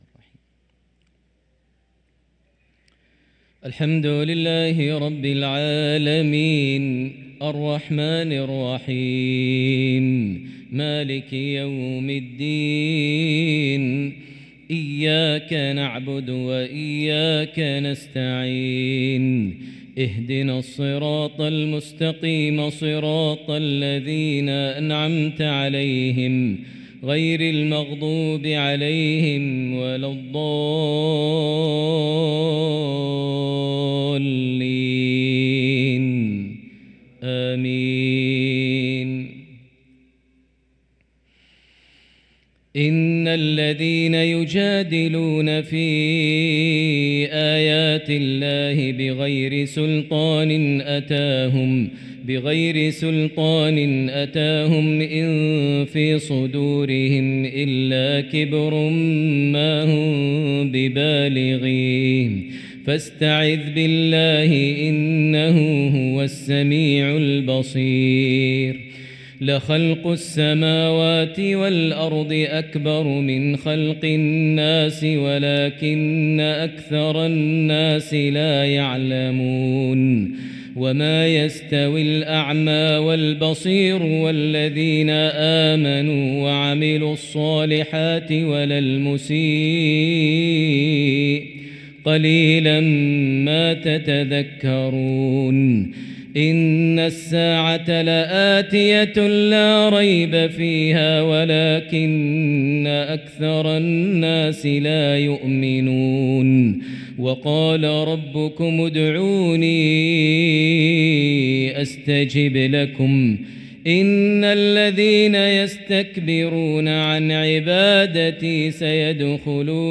صلاة العشاء للقارئ ماهر المعيقلي 3 جمادي الأول 1445 هـ
تِلَاوَات الْحَرَمَيْن .